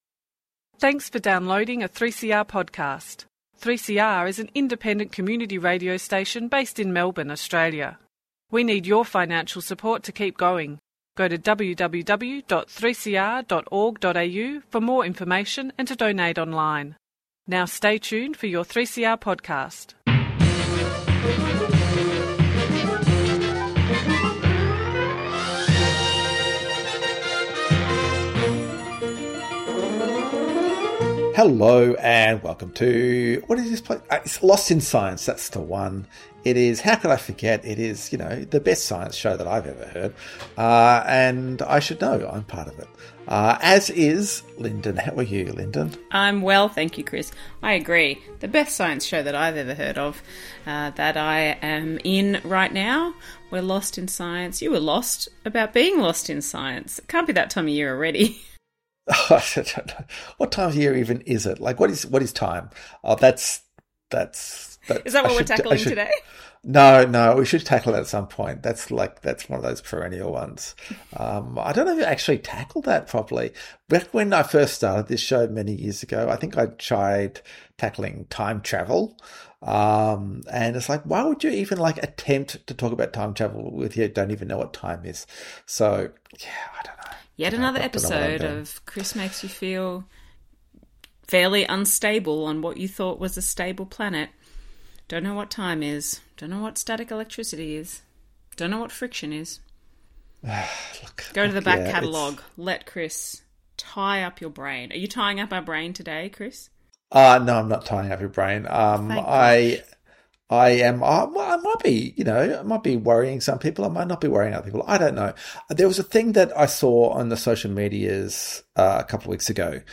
Tweet Lost in Science Thursday 8:30am to 9:00am Entertaining news and discussion about research that has impact on society and providing a wide range of science and technology news.